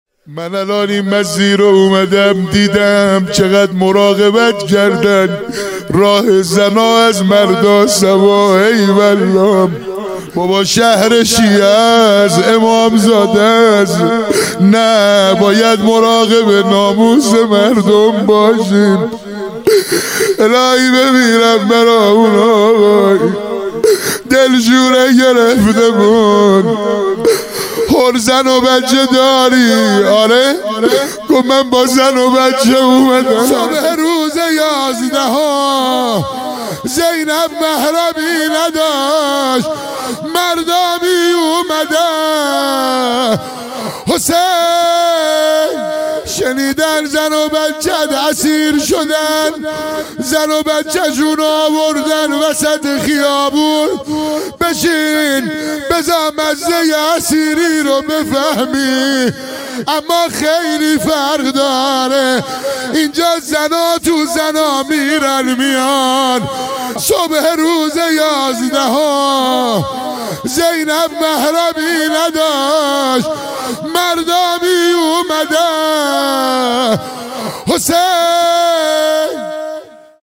زینب محرمی نداشت حیدر خمسه | چاووش محرم | تهیه شده توسط خانه هنر پلان 3